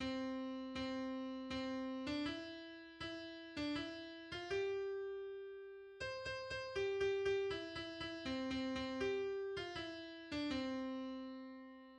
Row, Row, Row Your Boat (Rame, rame, rame dans ton bateau) est une chanson enfantine (nursery rhyme) américaine populaire, souvent chantée en boucle.